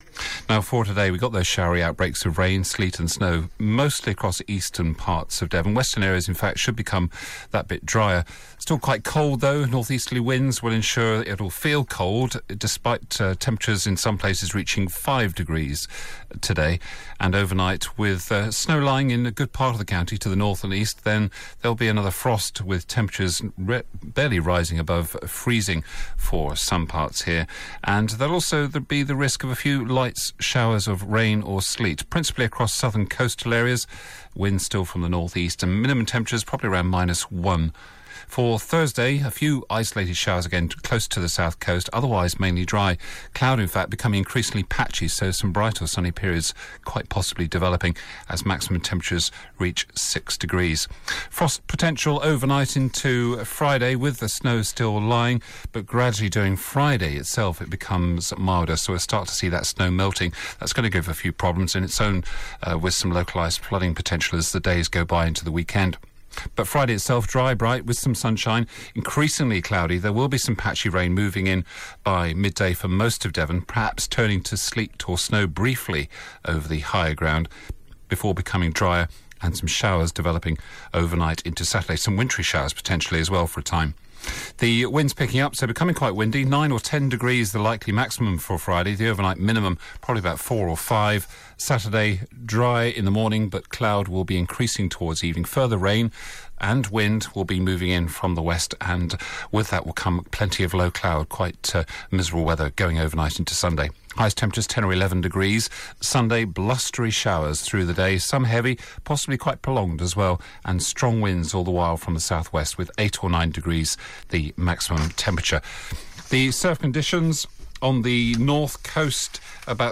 5 Day forecast for Devon